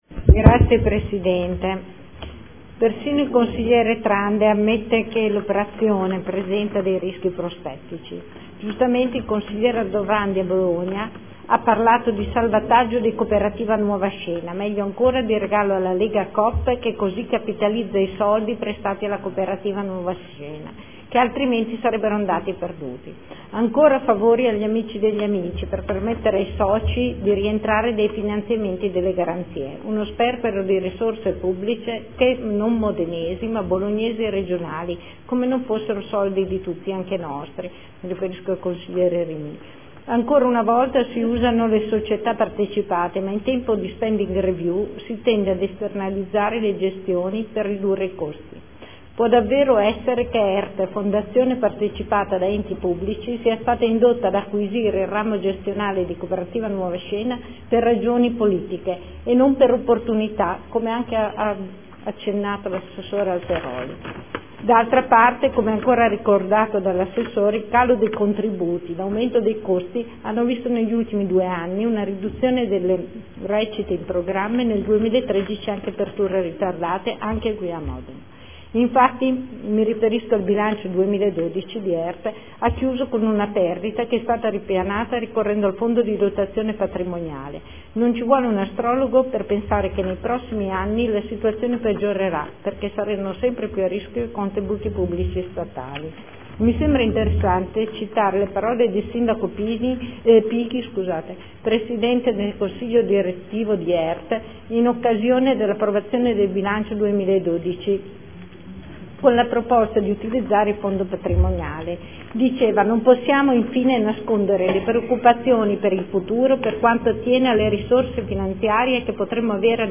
Seduta del 23/01/2014 Dichiarazione di Voto. Adesione del Comune di Bologna a Emilia Romagna Teatro Fondazione in qualità di socio fondatore necessario.